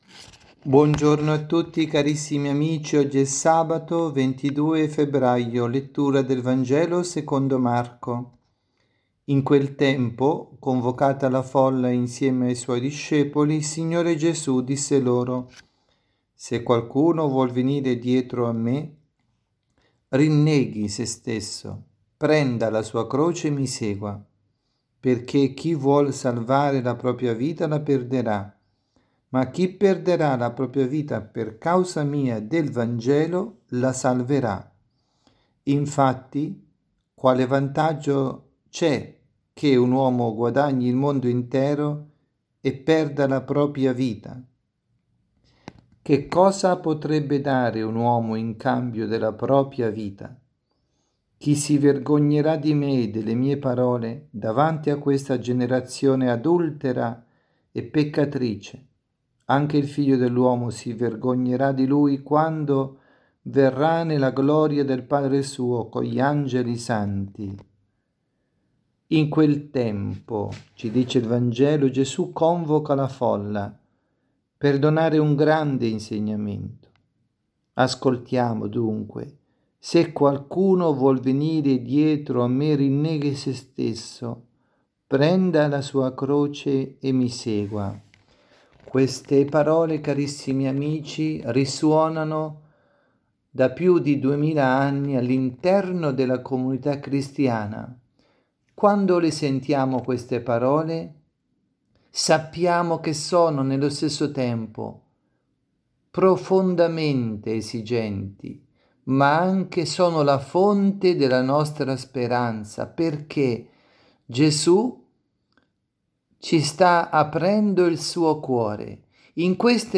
Omelia
Messa vigiliare di Domenica 23 febbraio 2020